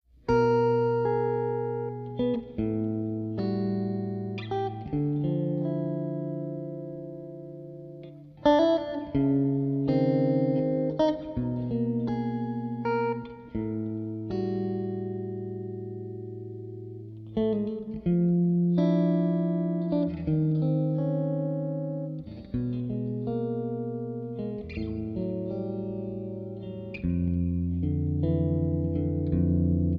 Guitar
Harmonica